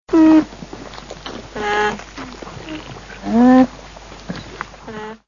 lama-lama-glama.mp3